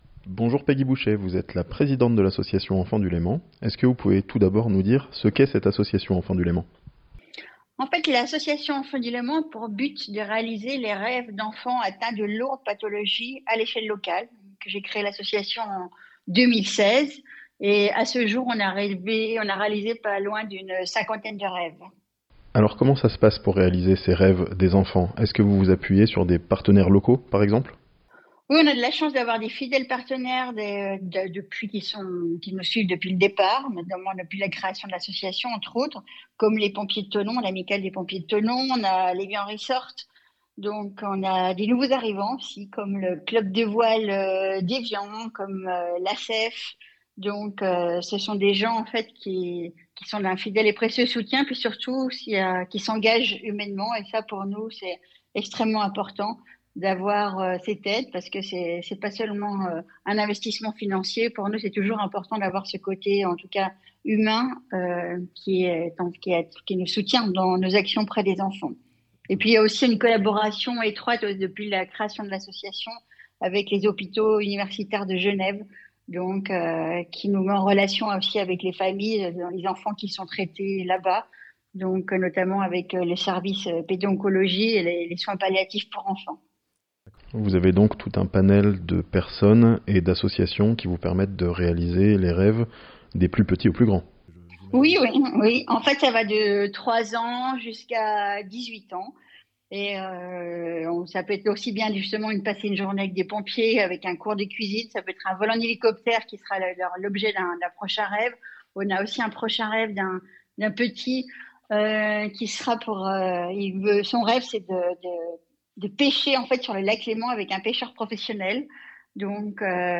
La grande fête des Enfants du Léman, c'est samedi à Amphion (interview)